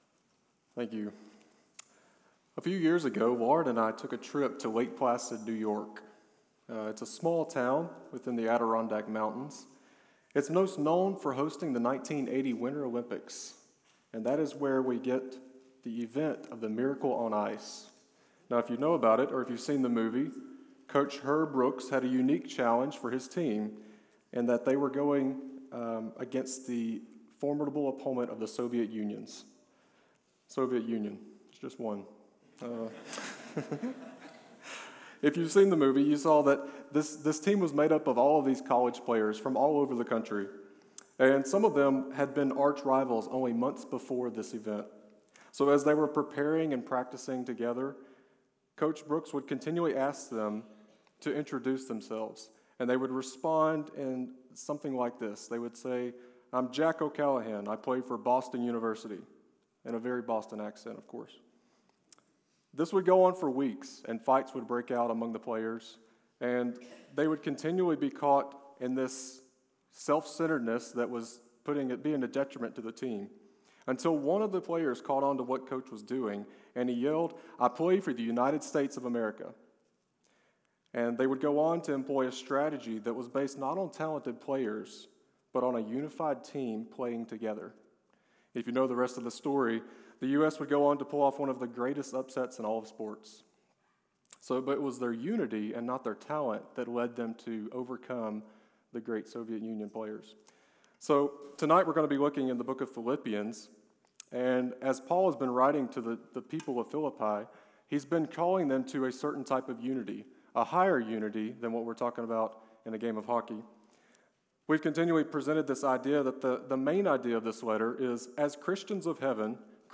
Sermons Sunday Evening Devotionals | University Baptist Church